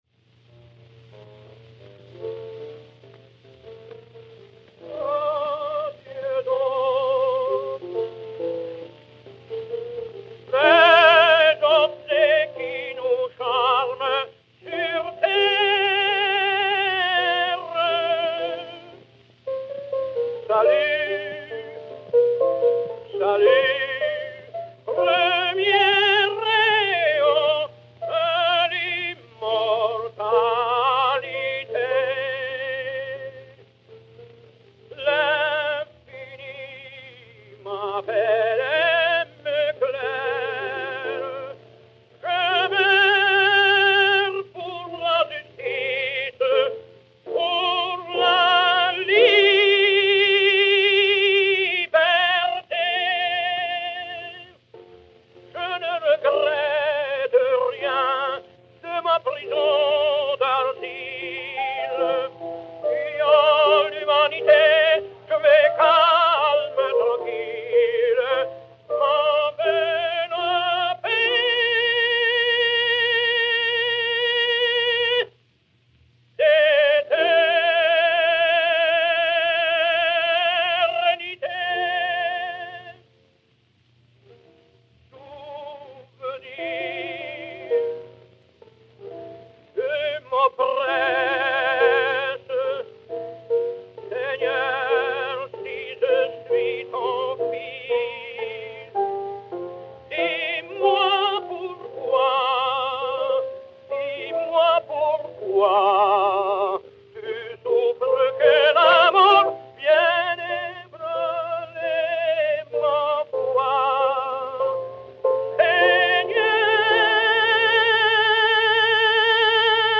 Francesco Tamagno (Jean) et Piano
Disque Pour Gramophone 52680, enr. à Milan en 1903